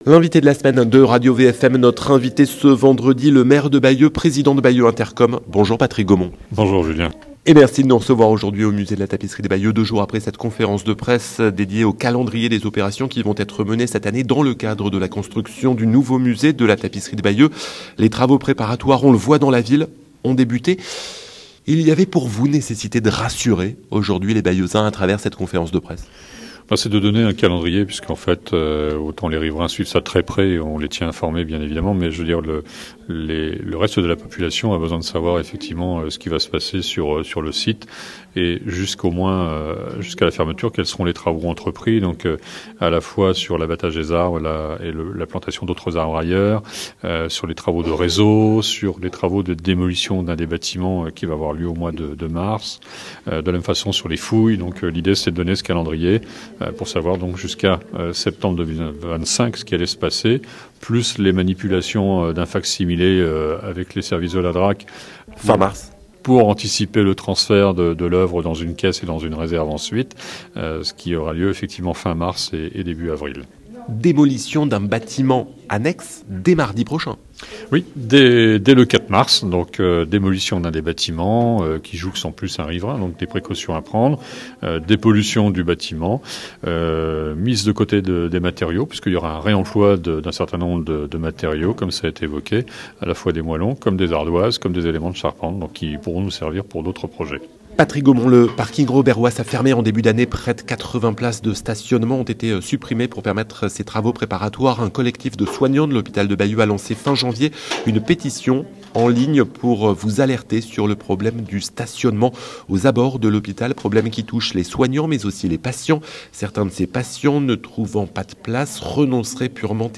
Patrick Gomont Patrick Gomont , le maire de Bayeux, président de Bayeux Intercom , invité de la semaine de RadioVFM